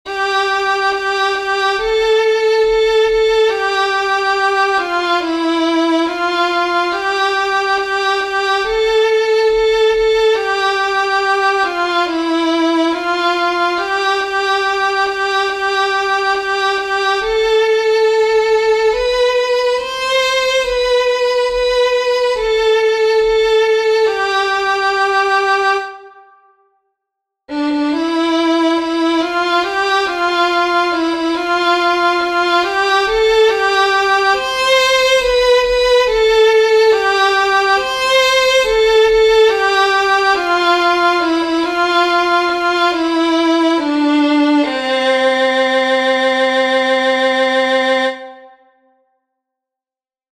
Exercise 3: 4/4 time signature.